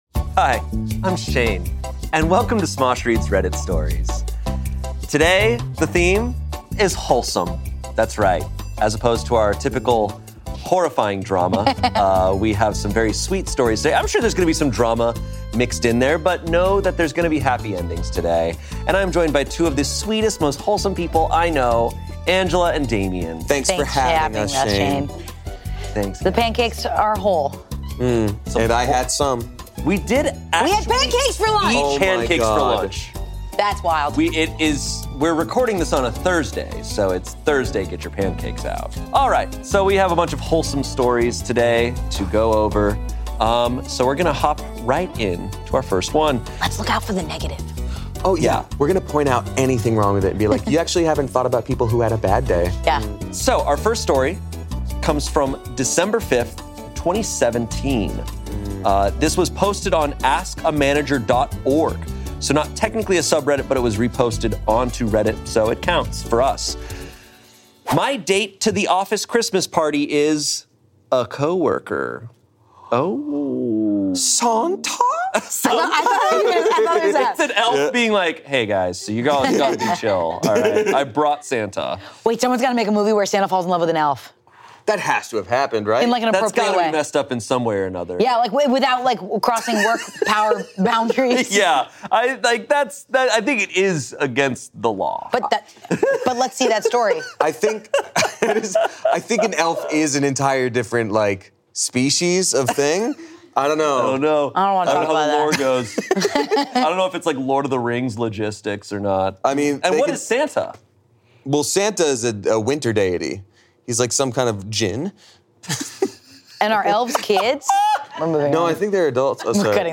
Finally A Happy Ending | Reading Reddit Stories